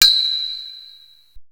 1da Tink.wav